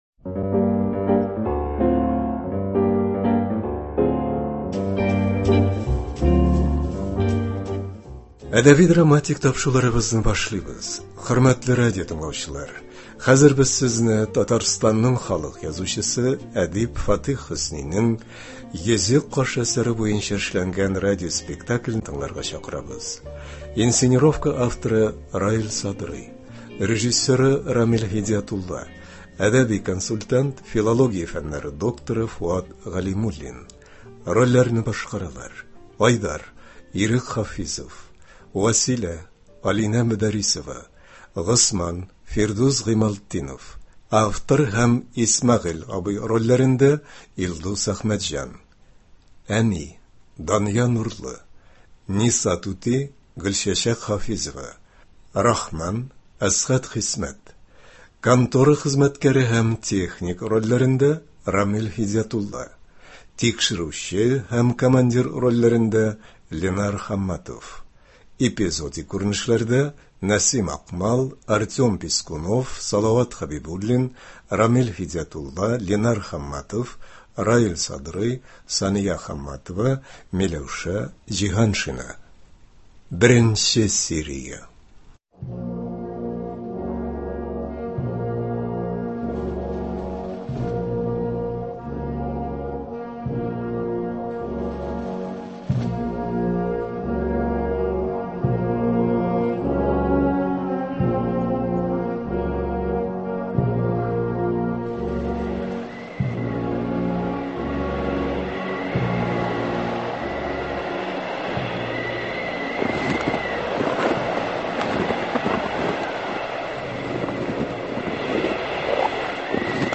“Йөзек кашы”. Радиоспектакль.